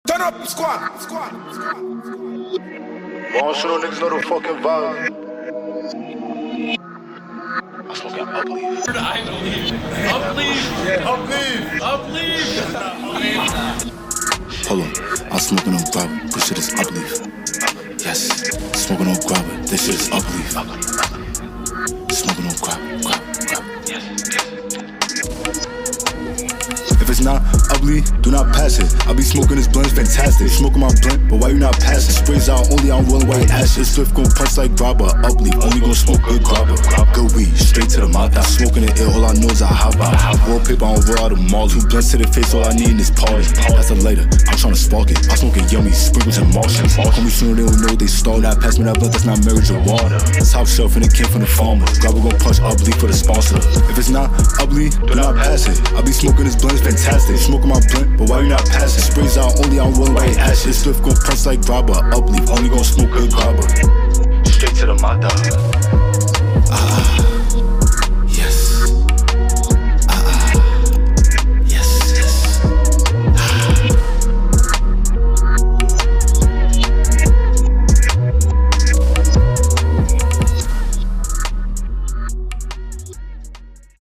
is all about high energy and flexing with confidence